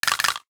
NOTIFICATION_Rattle_14_mono.wav